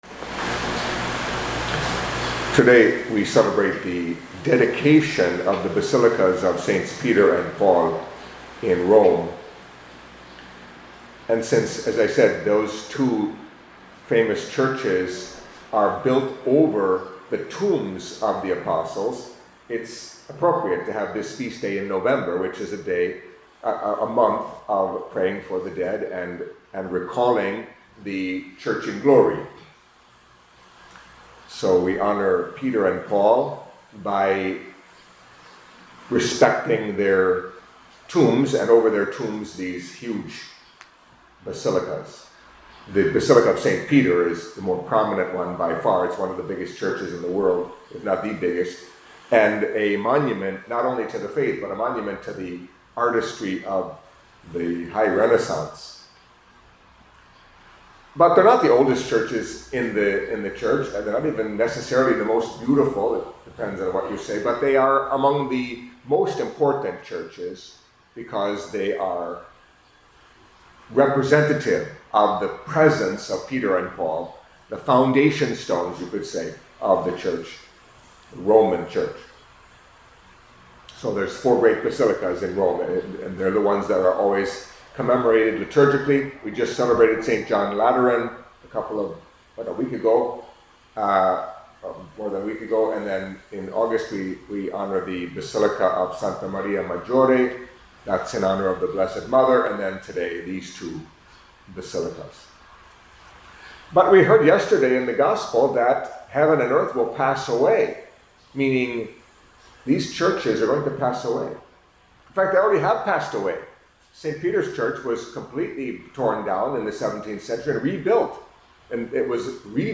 Catholic Mass homily for Monday of the Thirty-Third Week in Ordinary Time